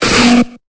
Cri d'Ymphect dans Pokémon Épée et Bouclier.